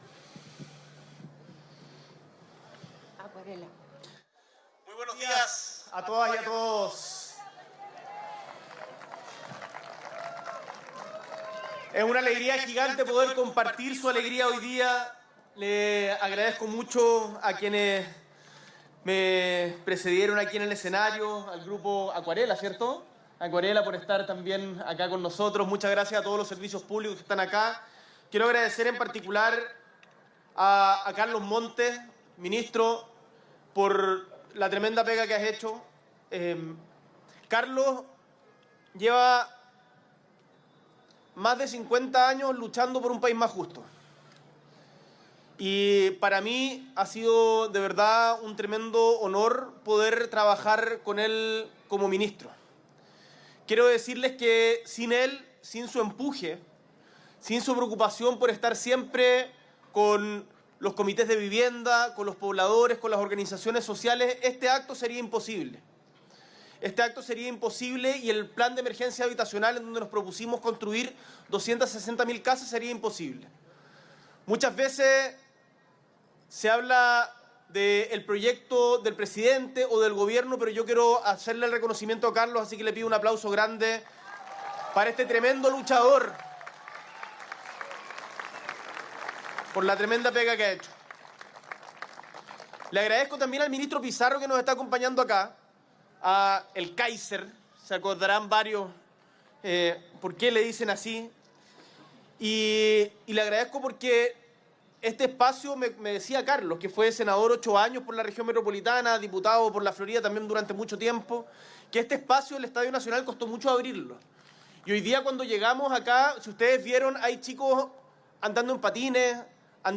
S.E. el Presidente de la República, Gabriel Boric Font, encabeza entrega de subsidios para familias de Sectores Medios (DS1)